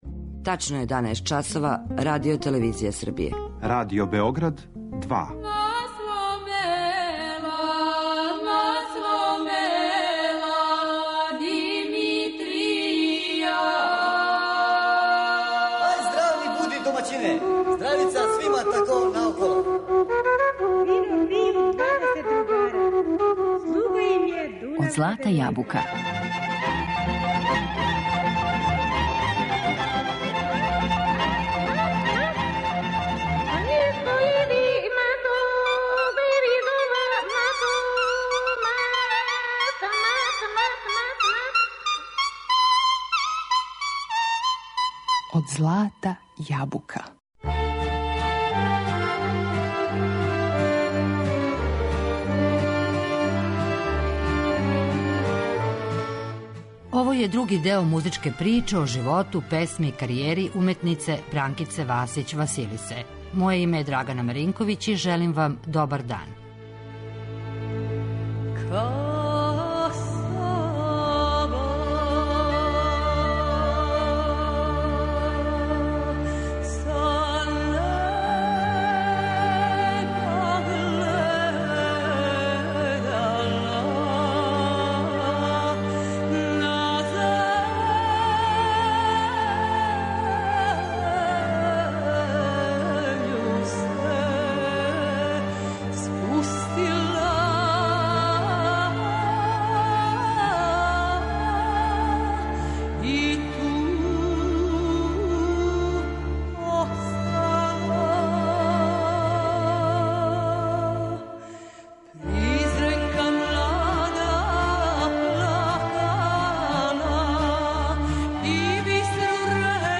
Својим вокалним способностиома скренула је пажњу јавности певајући старе, заборављене, изворне песме.